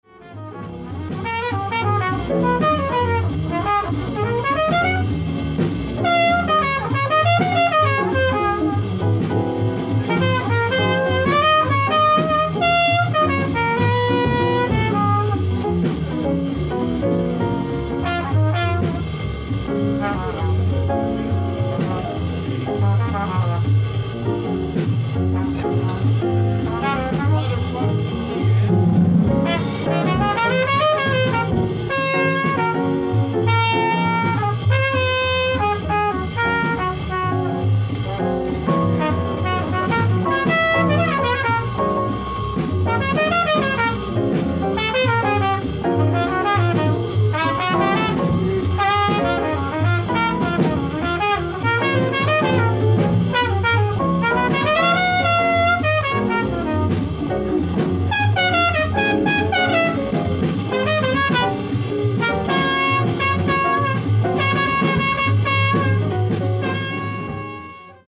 ライブ・アット・ラカンティーナ、ボローニア、イタリア
※試聴用に実際より音質を落としています。